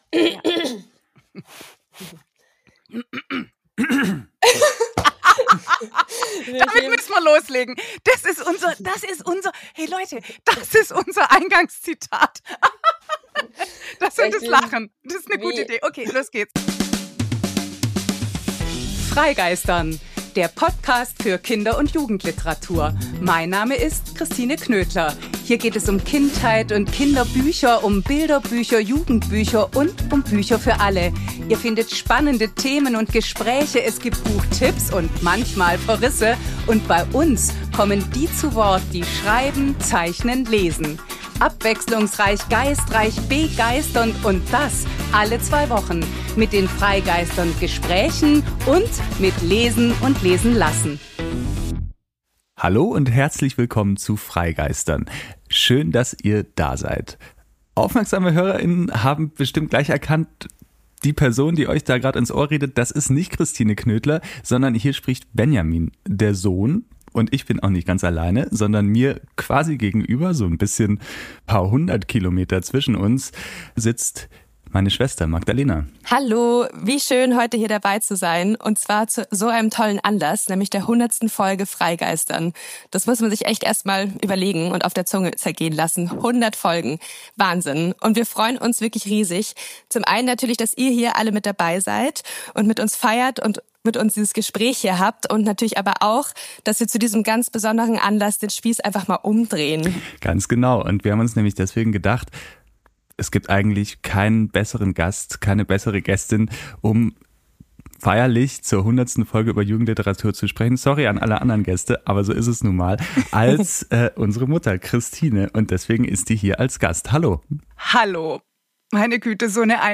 Das Ergebnis: Geballte Bücherliebe, Blicke hinter die Kulissen – und einen Ausblick gibt es natürlich auch. Wortreich, geistreich, begeisternd und mit viel Gelächter - typisch „freigeistern!“ eben!